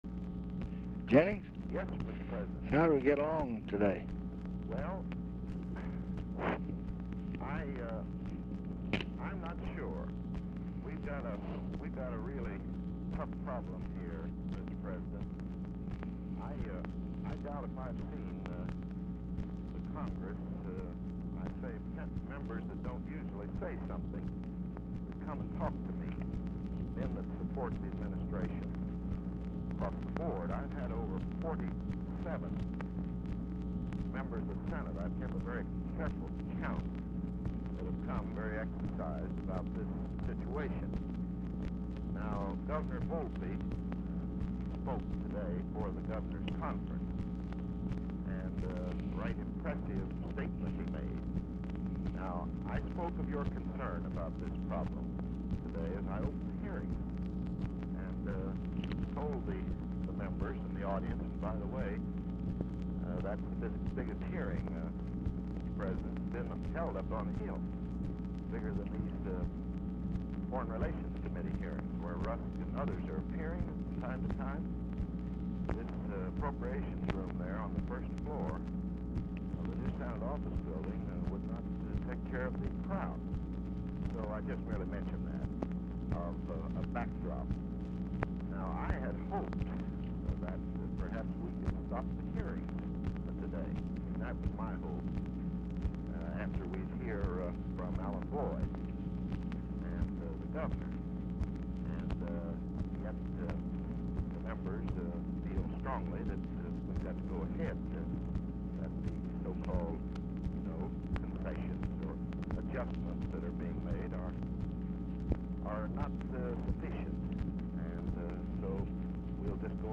ALSO RECORDED ON REF #11572 DUE TO POOR SOUND QUALITY, STICKING
Format Dictation belt
Specific Item Type Telephone conversation Subject Congressional Relations Economics Federal Budget Legislation National Politics Procurement And Disposal Transportation